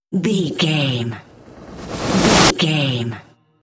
Trailer raiser
Sound Effects
Fast paced
In-crescendo
Atonal
futuristic
dramatic
riser